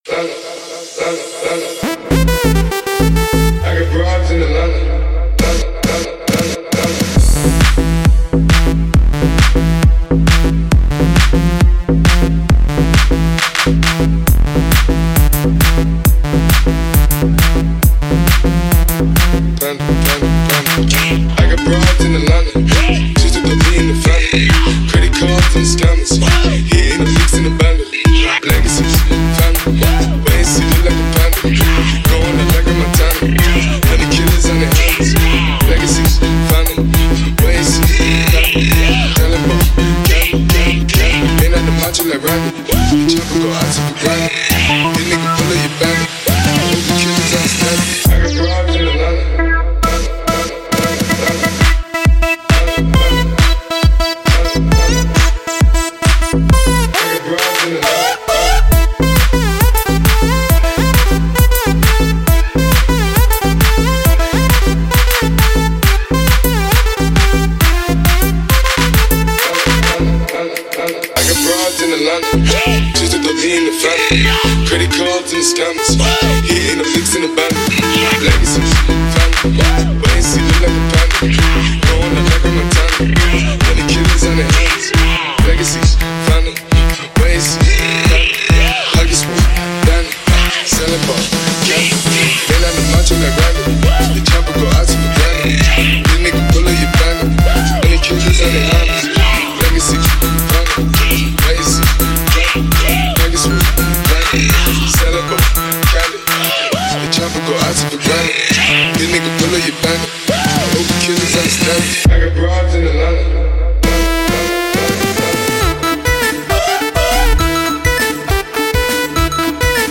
ریمیکس بندری